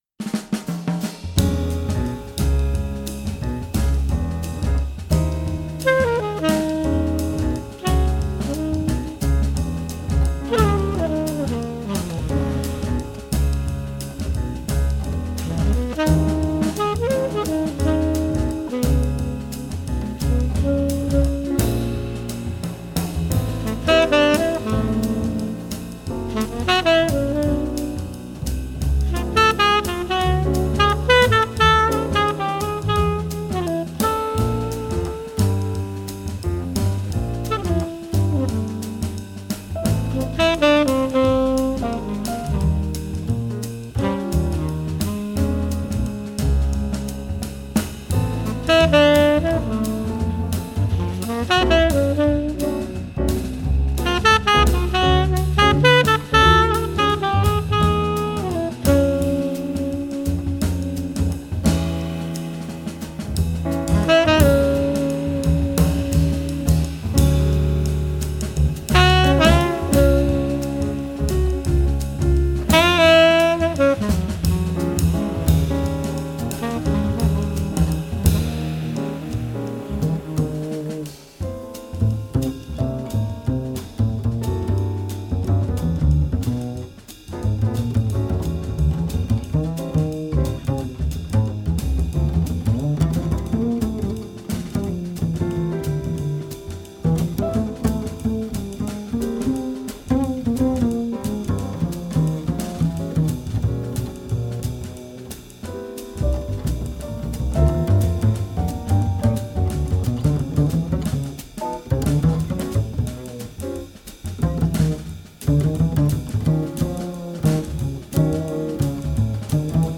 Trp
Flute & Altosax
Bassclarinet
Saxophones
Piano
Bass
Drums